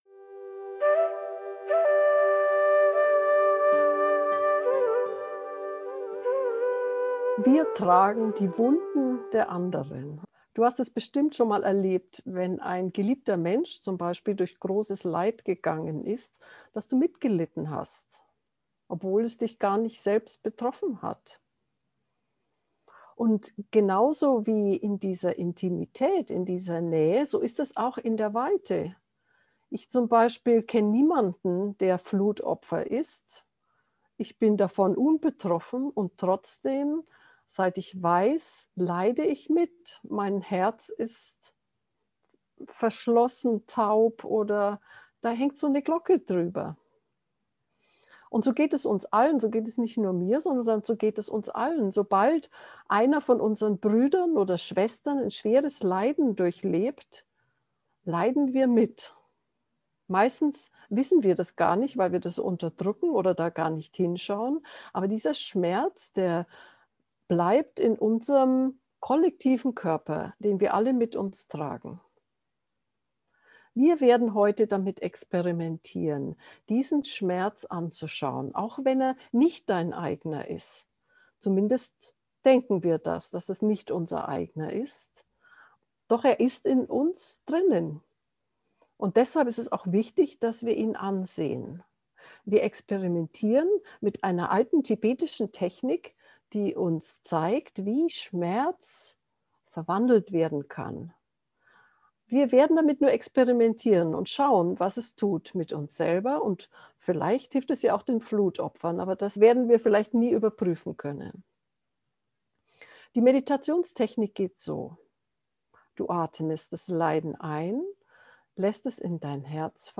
Meditationsanleitung zur geführten Meditation